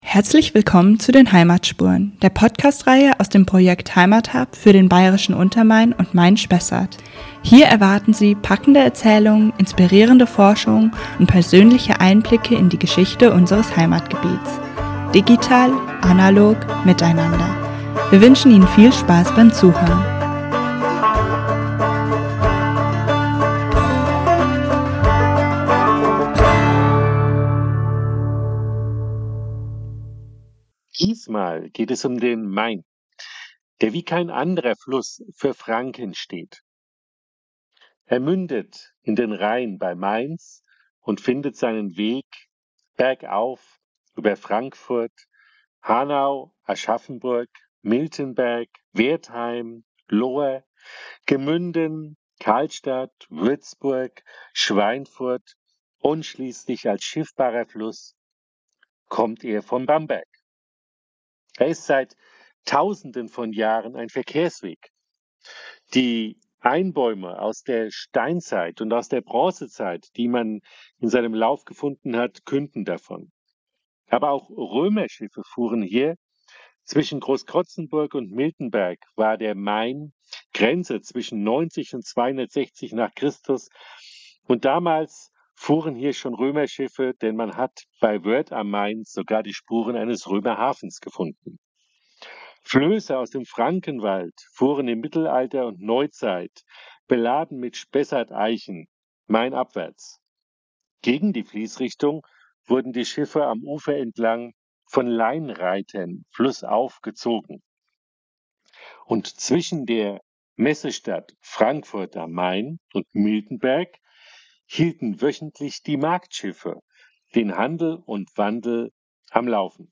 Aus dem Heimathub berichten Akteurinnen und Akteure, Mitwirkende, beteiligte Institutionen und die Macherinnen und Macher über spezielle Themen, geben Einblick hinter die Kulissen und vertiefen Artikel, die bereits im Journal veröffentlicht wurden.